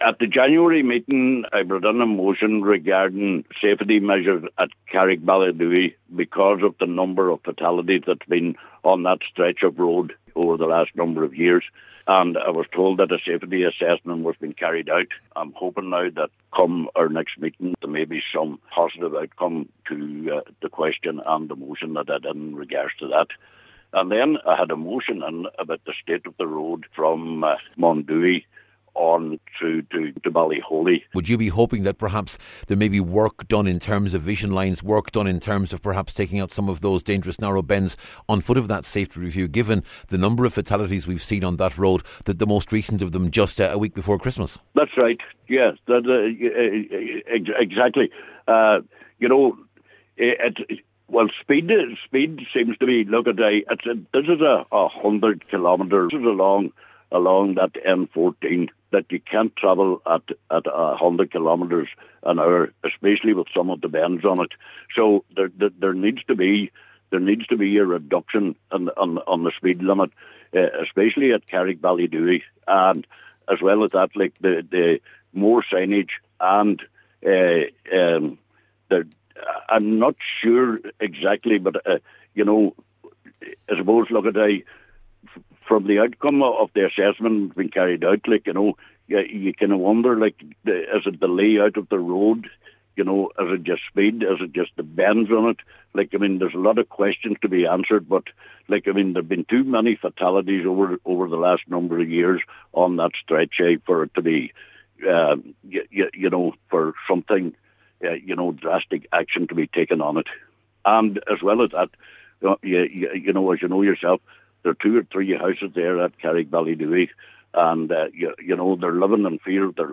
A Letterkenny councillor says safety works must take place on the N14 Manorcunningham to Lifford Road in response to a number of fatalities, the most recent of them just before Christmas.
Cllr Coyle says that cannot be an excuse for inaction, and the impending safety review must be acted on……..